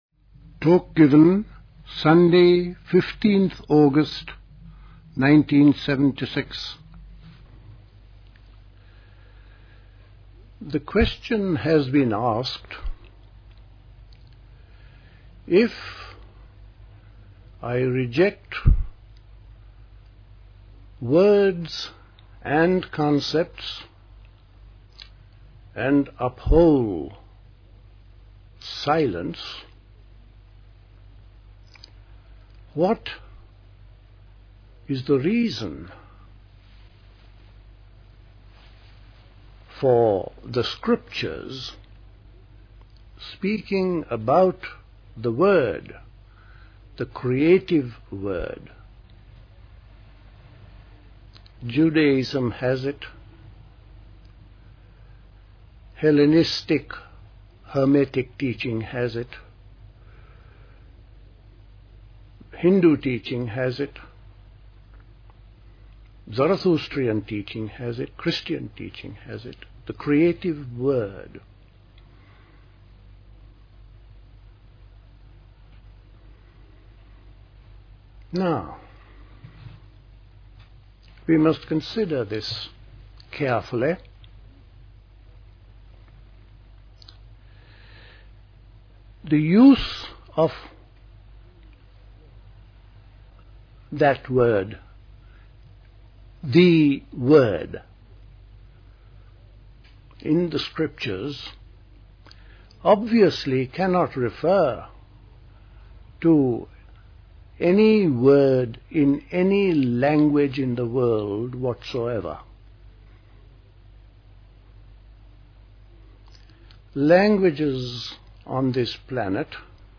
A talk
at Dilkusha, Forest Hill, London on 15th August 1976